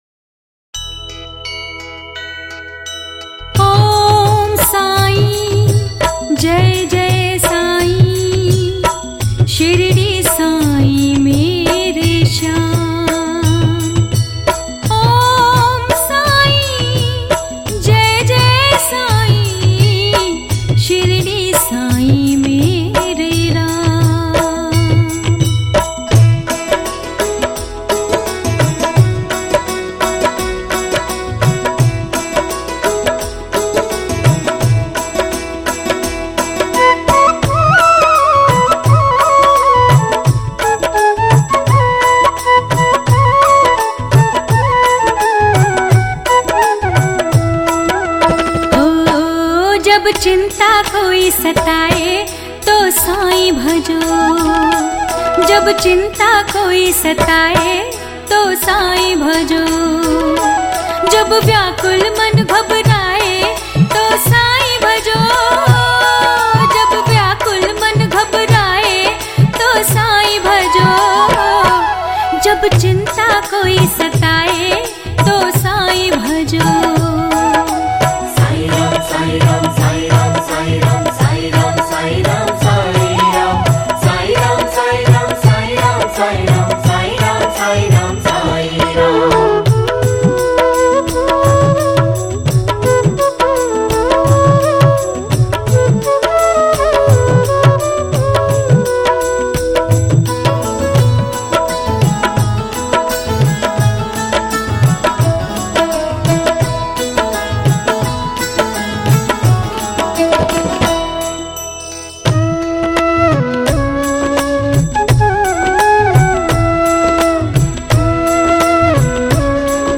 Sai Baba Bhajan
Hindi Bhajan